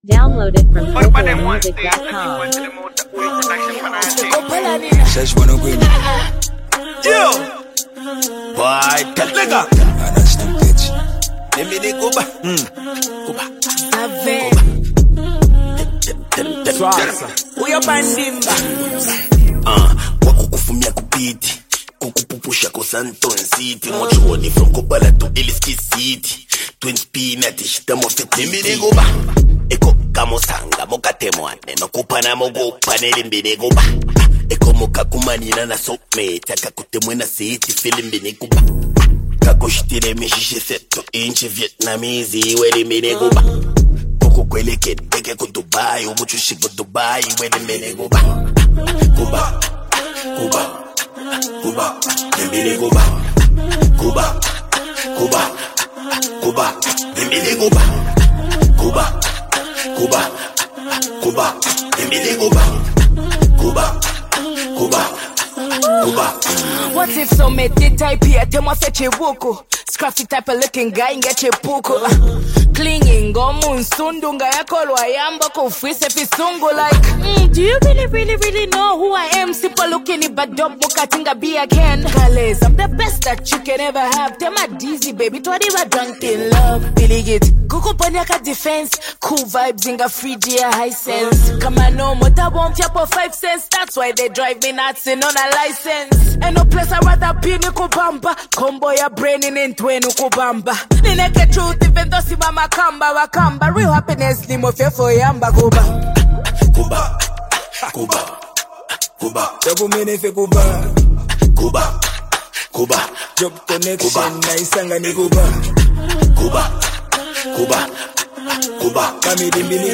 vibrant Zambian hip-hop anthem
confident delivery and street-rooted lyrics
trademark witty bars and smooth flow
giving the song a catchy and polished feel.
and party vibes
club banger